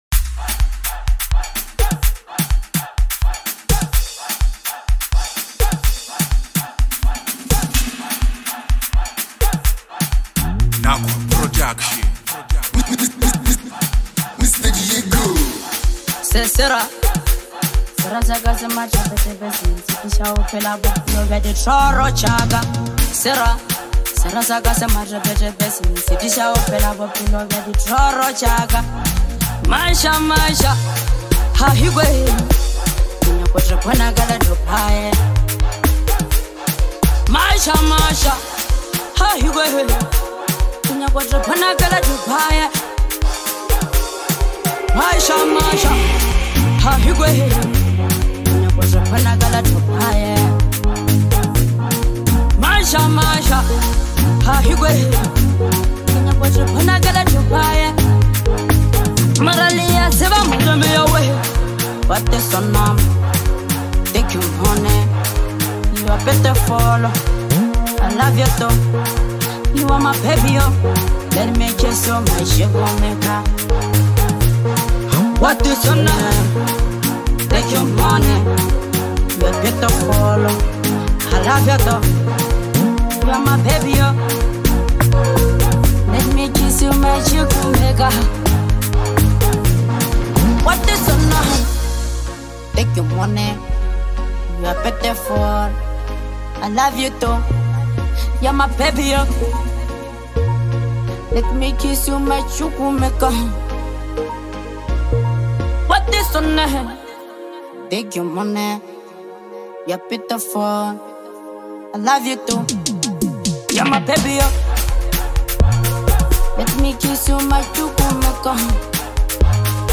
a high energy anthem
upbeat tempo and lively lyrics